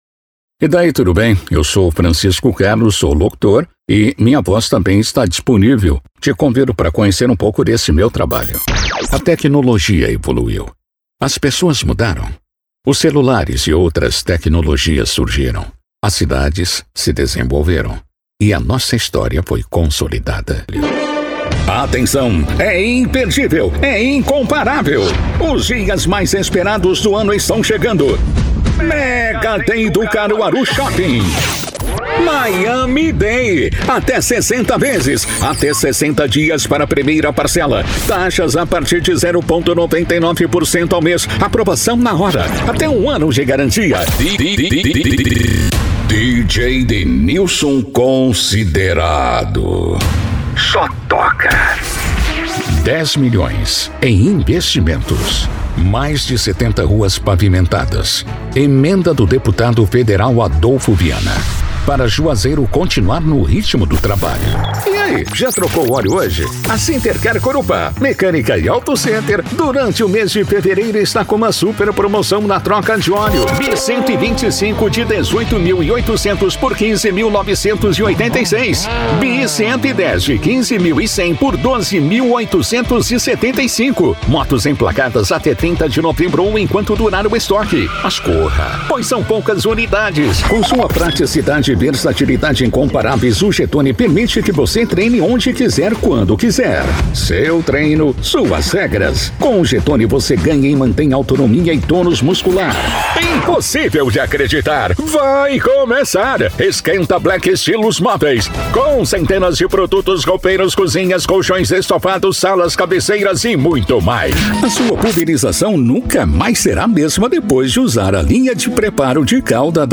VOZES MASCULINAS
Estilos: Padrão Institucional